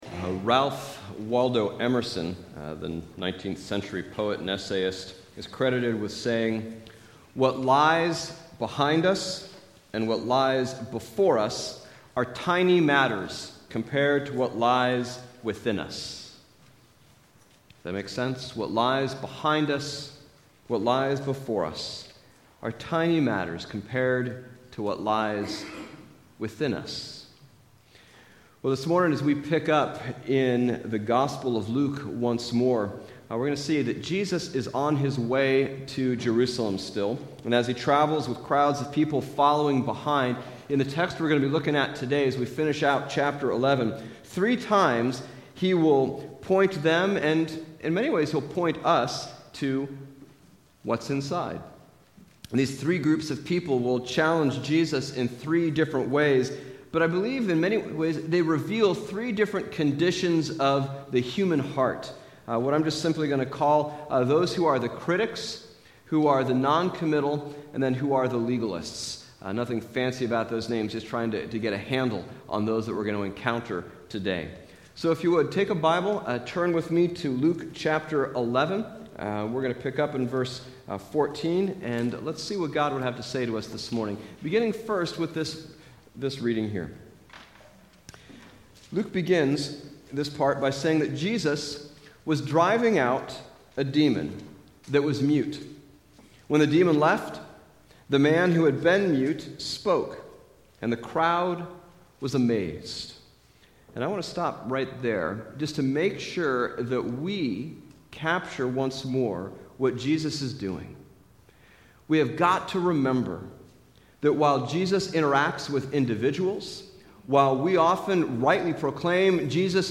Bible Text: Luke 11:14-54 | Preacher